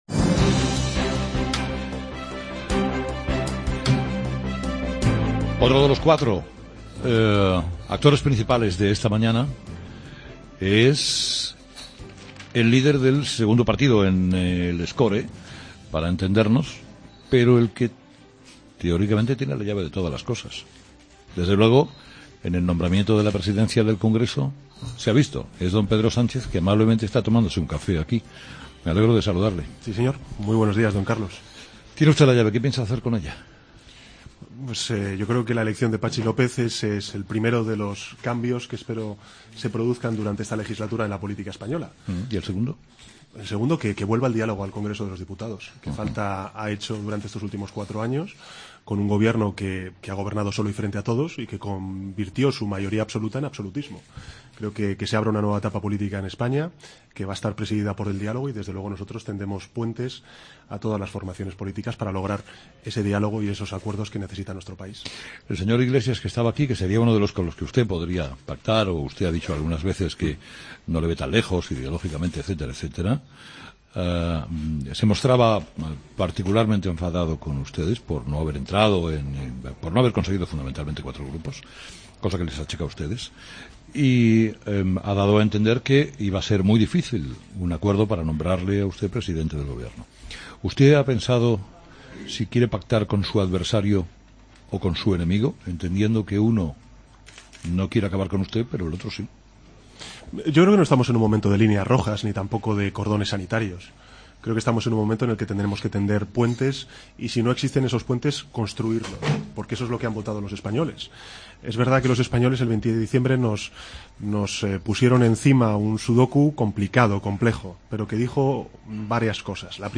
AUDIO: Escucha la entrevista a Pedro Sánchez en 'Herrera en COPE' desde el bar Manolo